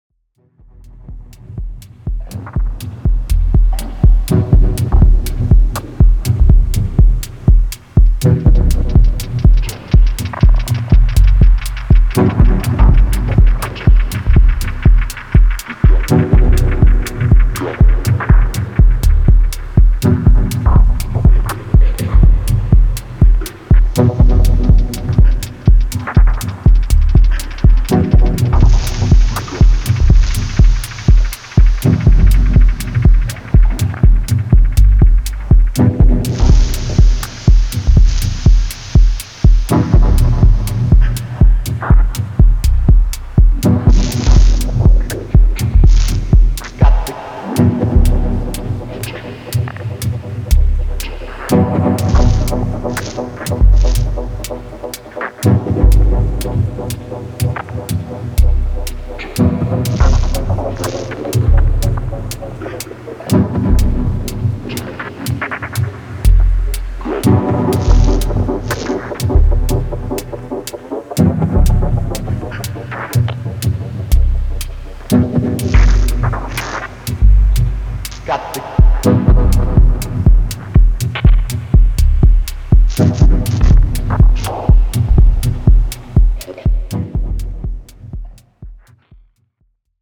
experimental live project